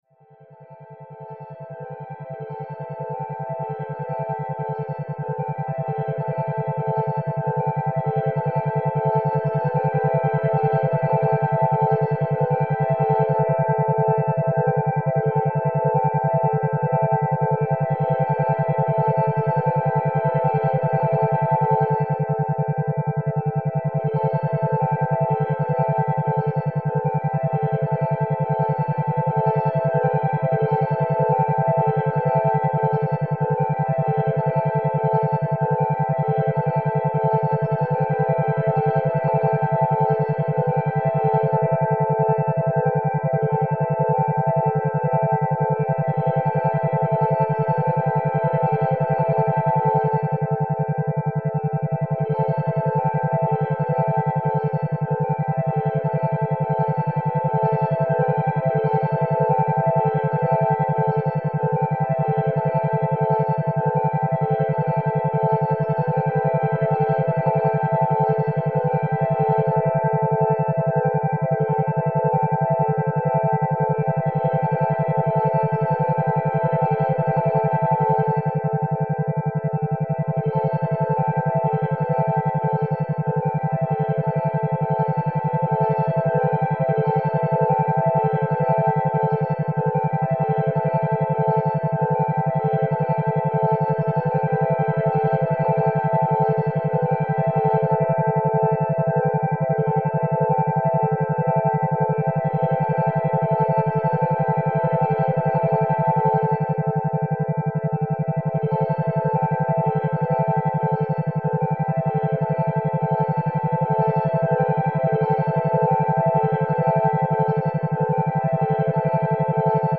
Each session is designed using the latest methods of delivering effective MP3 brainwave entrainment to you. Weight Loss sessions use various beta waves to increase metabolism and reduce carbohydrate cravings.
Is a 30 minute headphone session which includes affirmations regarding healthy eating, eating less and having a positive outlook!
Relax, let yourself go with the beats!
PREVIEWS-RELAXMP3--Session-weight-loss-affirmations.mp3